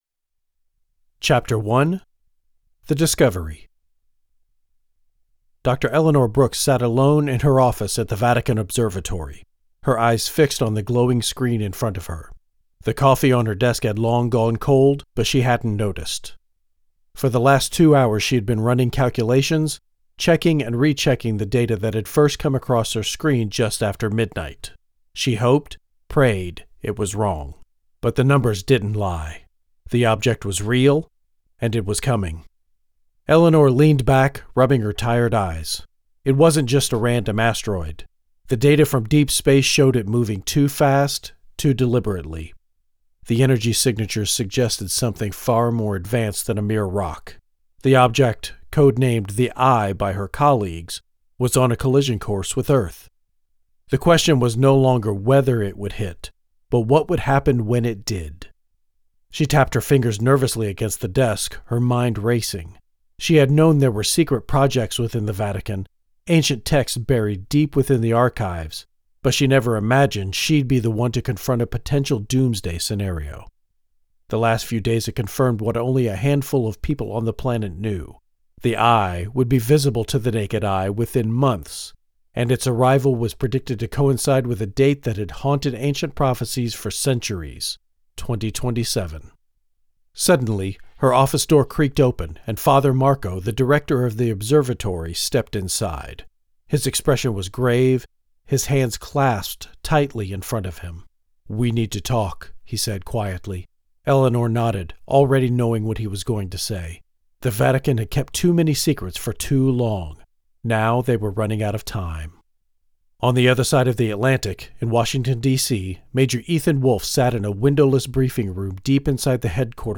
Audiobook Chapter Sample